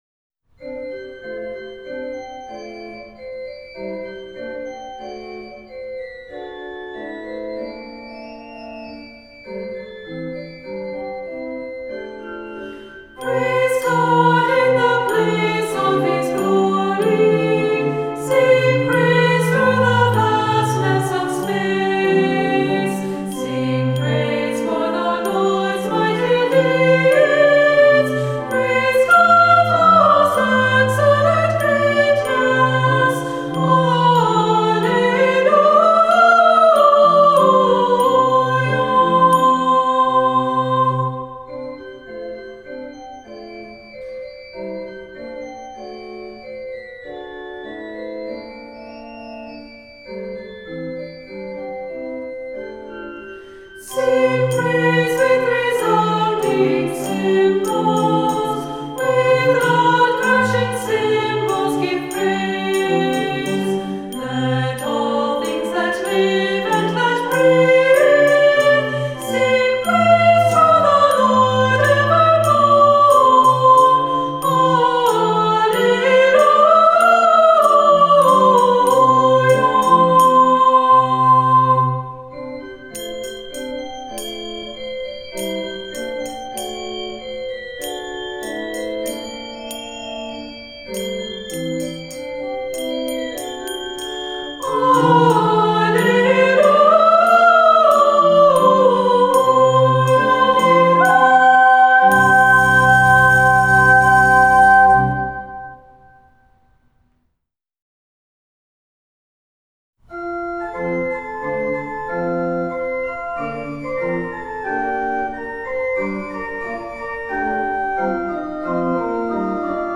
Voicing: Treble